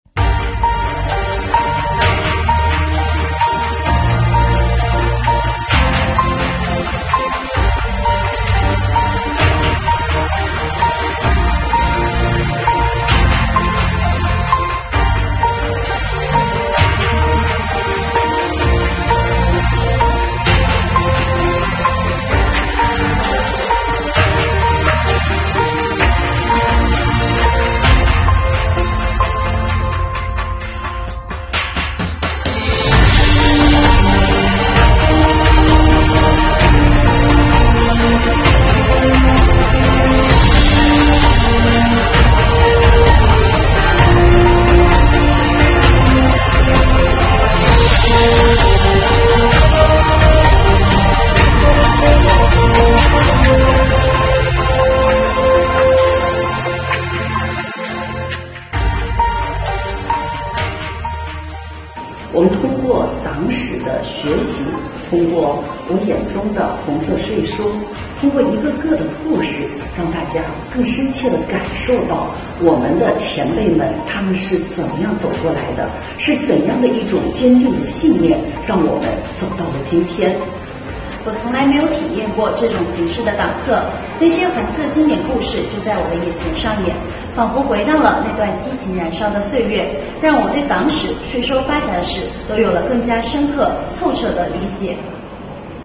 5月13日，由南宁市税务系统青年干部自编自导自演的“我眼中的红色税收”沉浸式主题党课在南宁市税务局开讲。